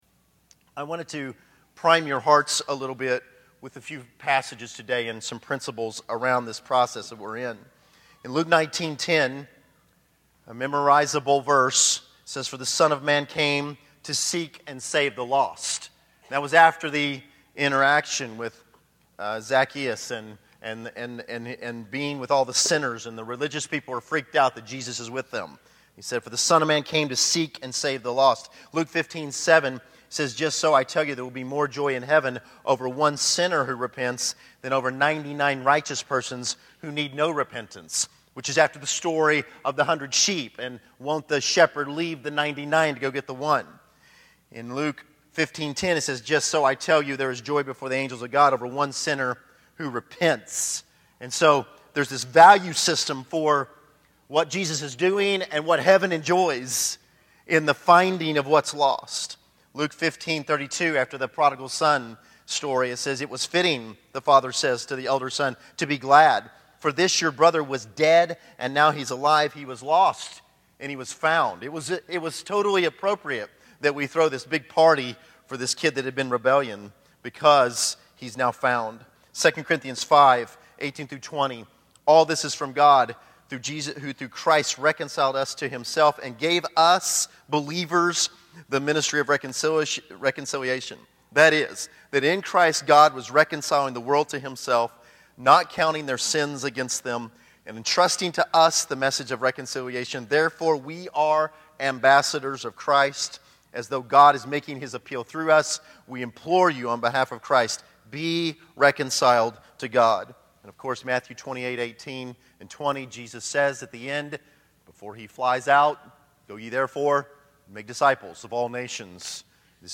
Personal Evangelism February 17, 2013 Category: Sermons | Back to the Resource Library Five Kingdom Keys to unlock a lifestyle of personal evangelism.